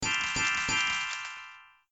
threeSparkles.ogg